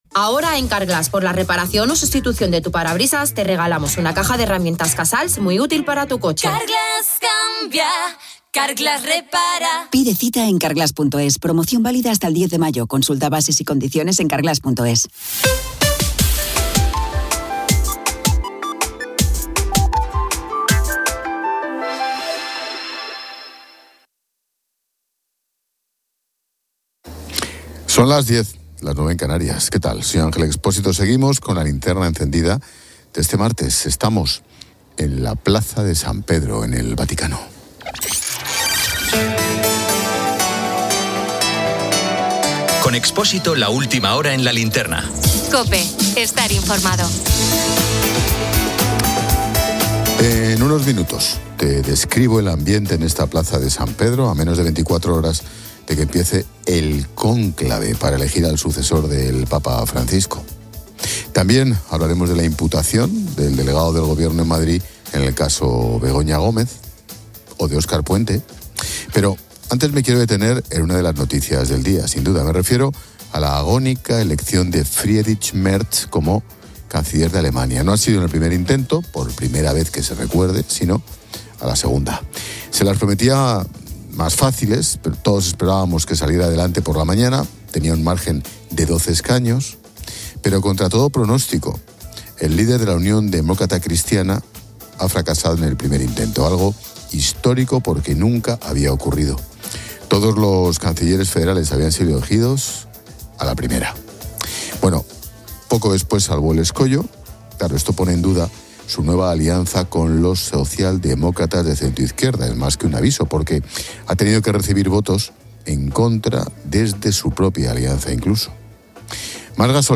Estamos en la Plaza de San Pedro en el Vaticano.